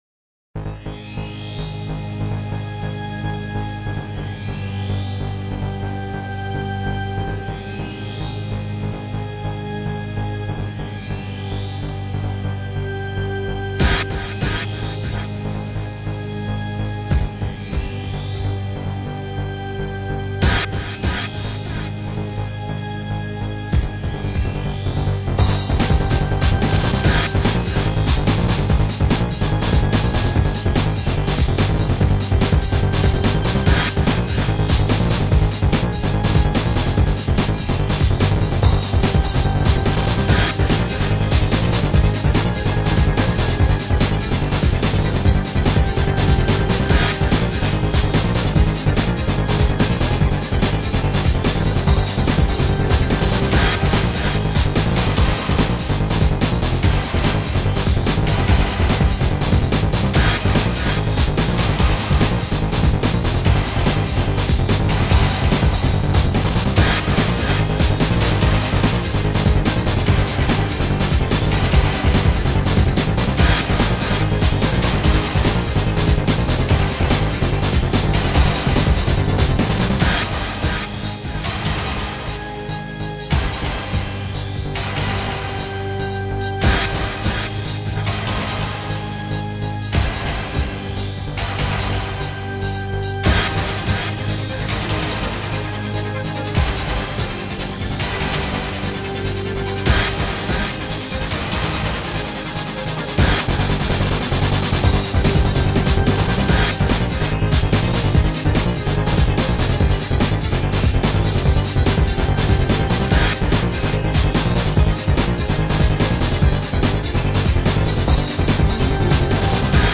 内容はブレイクビーツ。
ベース強め設定。